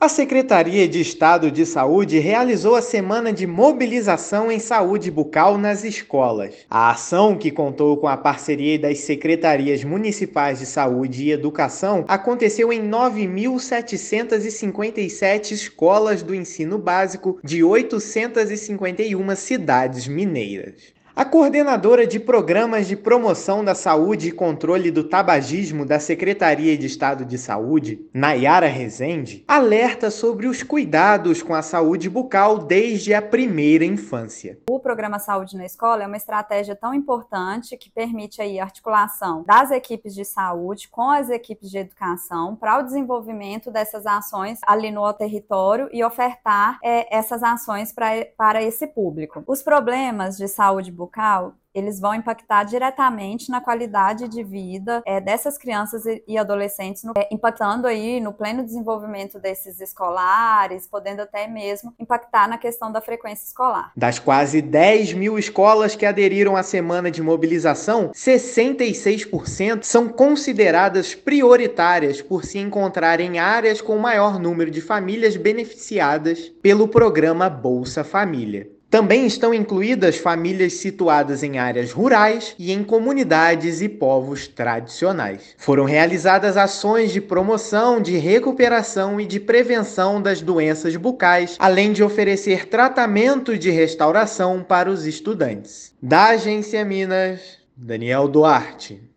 [RÁDIO] Saúde estadual promove semana de mobilização em saúde bucal